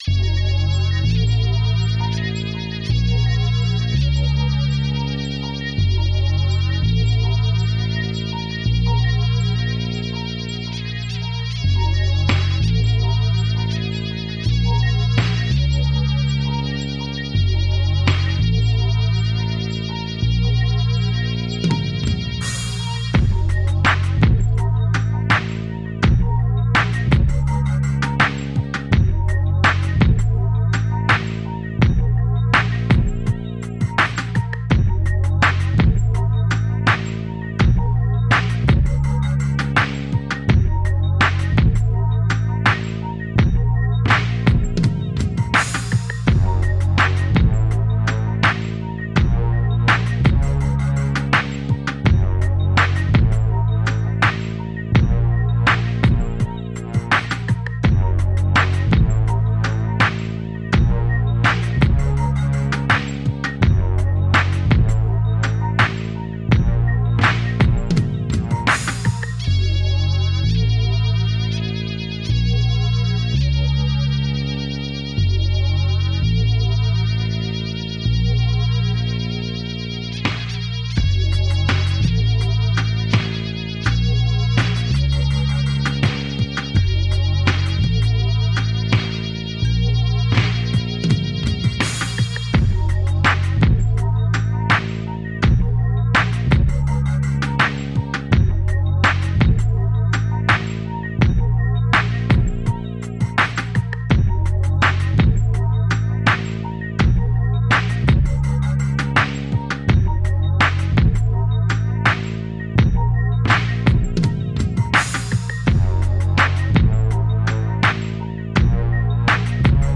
Dub, reggae, electronica, downtempo, chill out, trip hop.